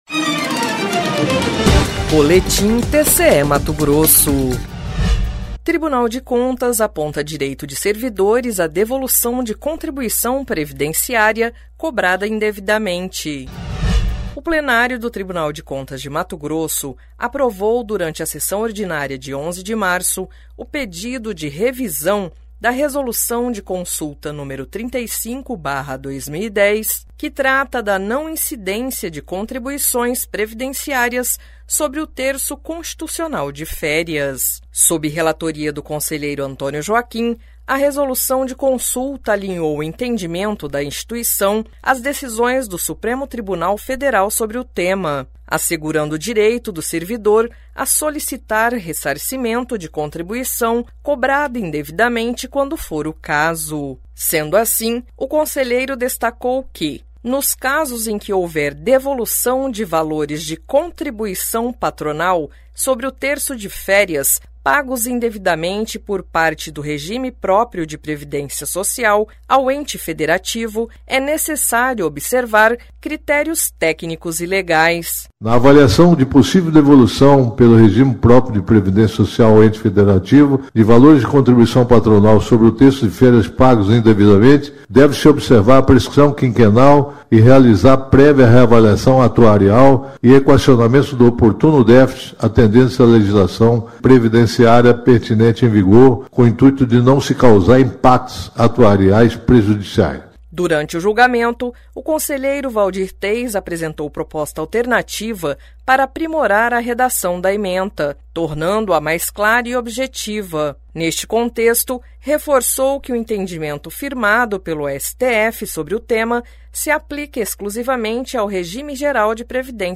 Sonora: Antonio Joaquim – conselheiro do TCE-MT
Sonora: Waldir Teis – conselheiro do TCE-MT